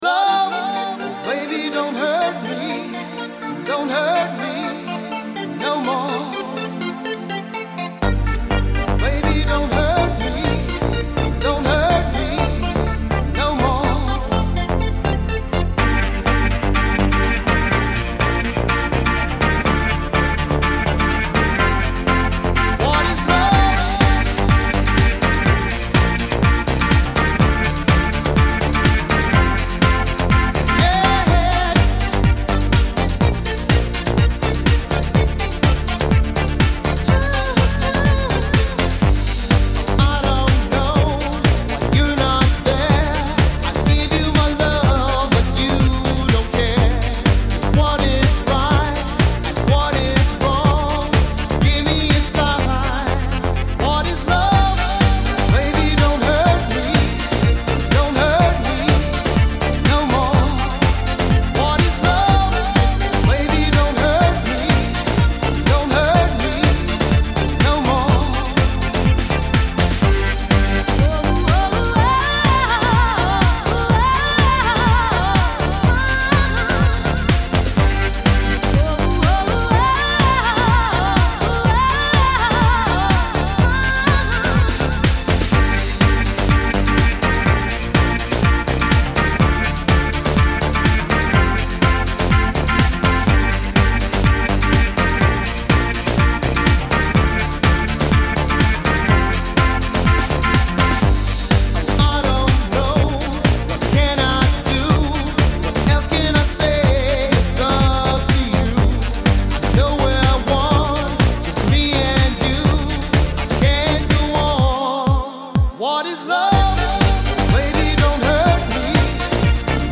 EDM 90er